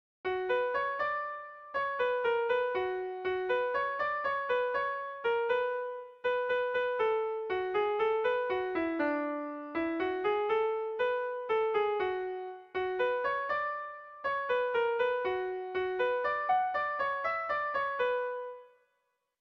Bertso melodies - View details   To know more about this section
AABAD